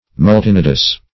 multinodous - definition of multinodous - synonyms, pronunciation, spelling from Free Dictionary
Multinodous \Mul`ti*no"dous\, a.